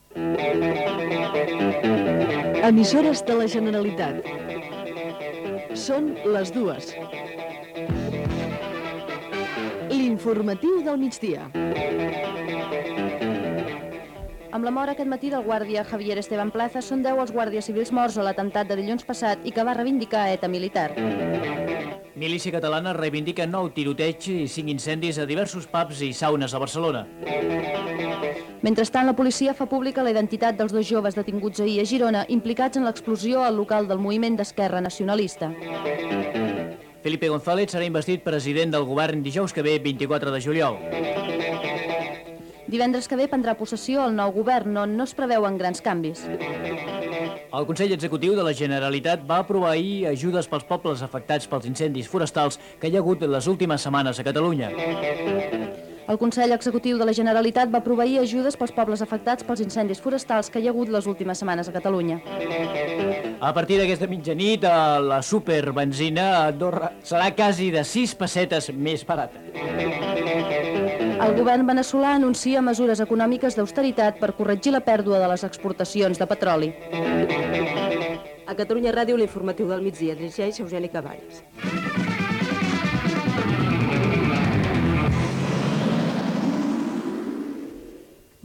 Identificació com "Emissores de la Generalitat" hora, indicatiu del programa, sumari informatiu
Informatiu
FM